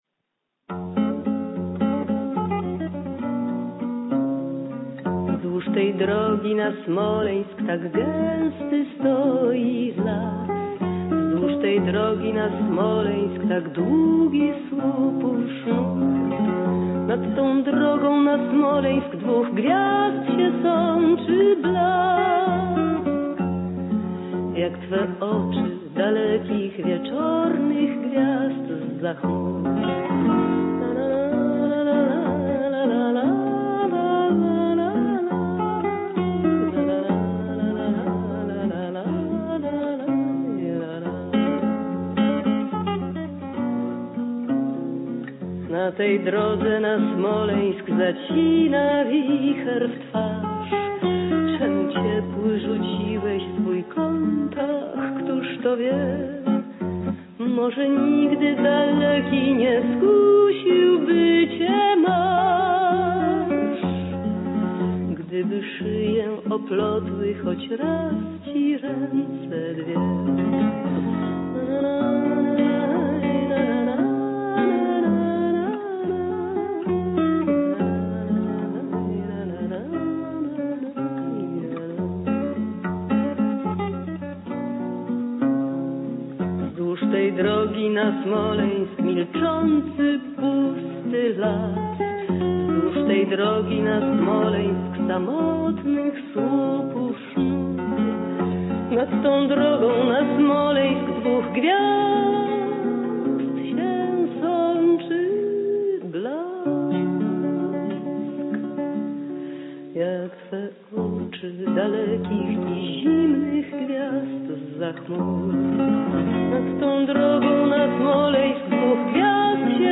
На гитаре аккомпанирует известный польский композитор